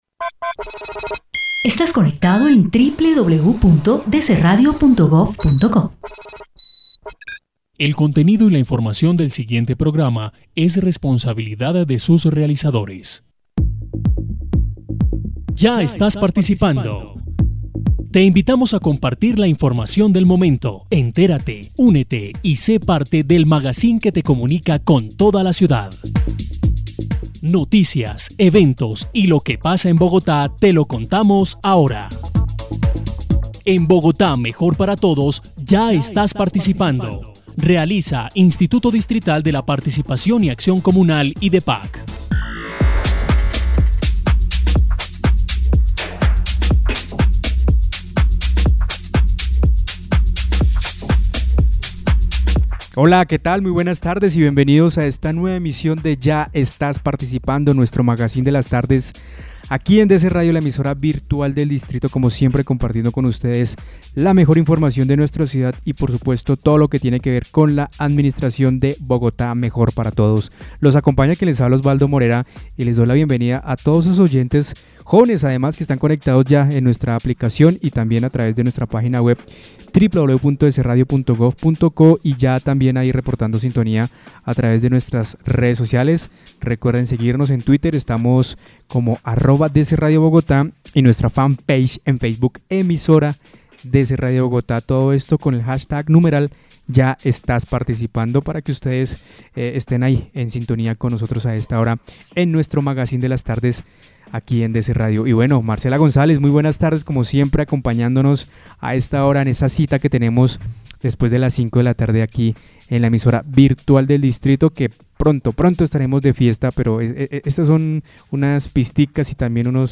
programa magazine de la tarde juvenil